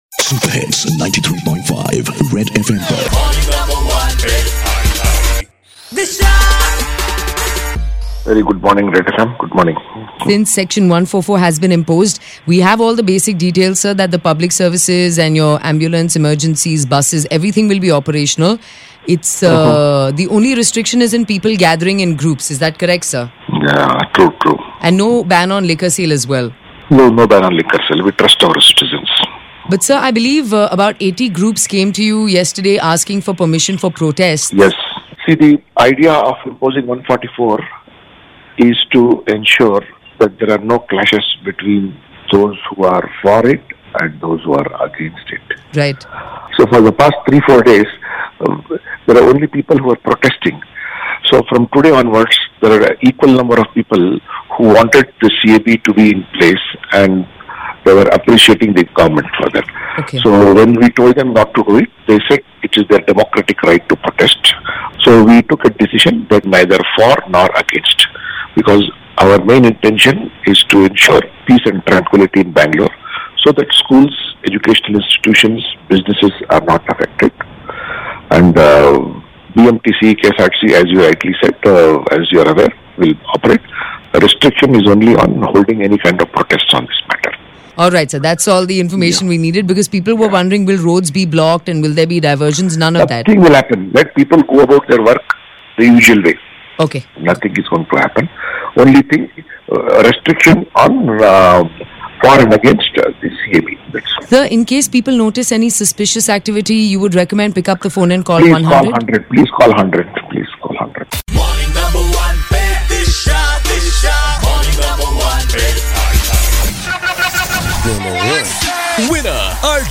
speaks to Mr. Bhaskar Rao, Commissioner of Police, Bengaluru City Police